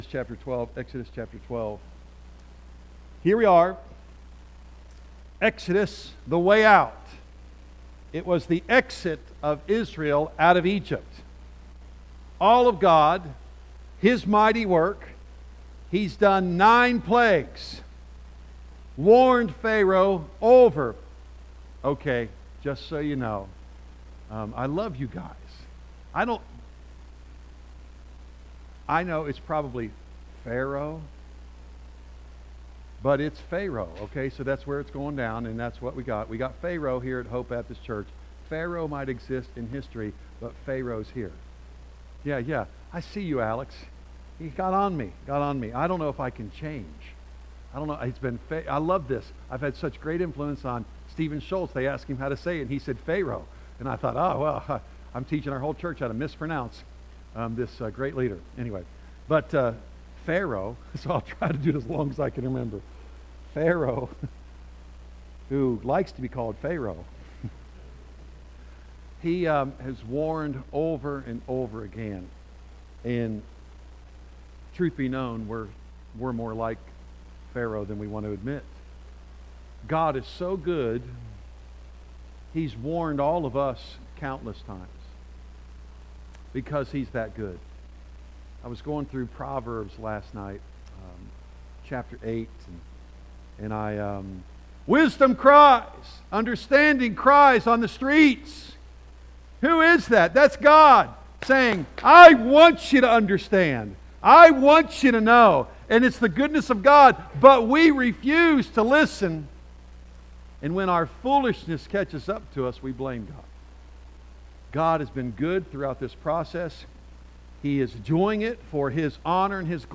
A message from the series "Exodus."